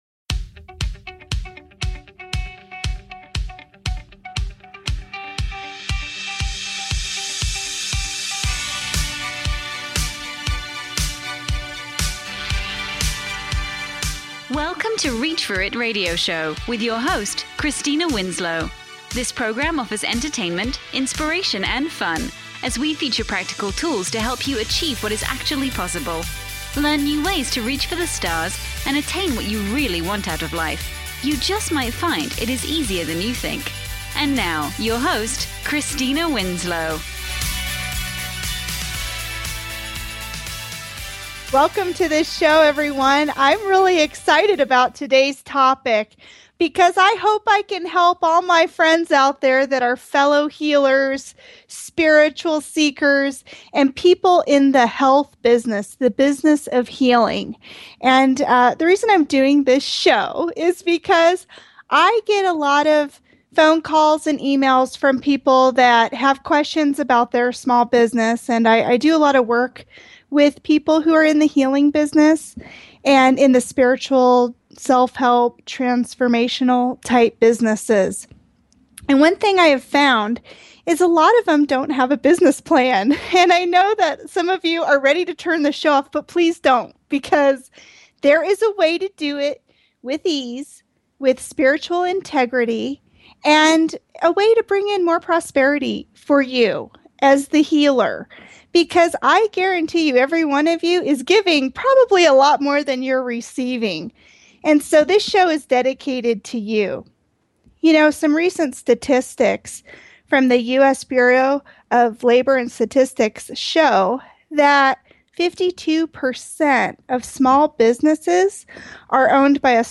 Listeners are incouraged to call in with their questions about how to grow their healing business on this live show.